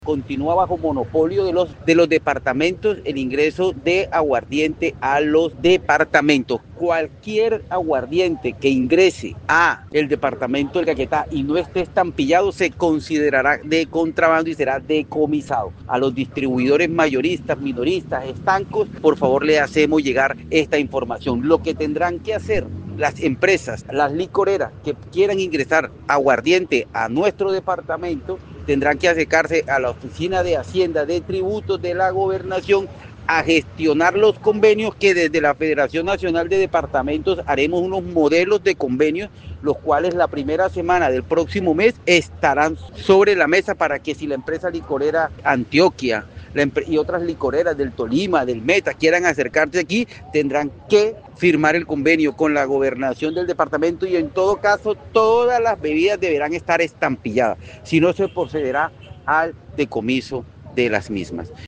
Así lo dio a conocer el gobernador de Caquetá, Luis Francisco Ruiz Aguilar, al indicar que, junto a la federación nacional de departamentos, se construirá un modelo de convenio, donde se estipulen las condiciones para el ingreso de productos foráneos.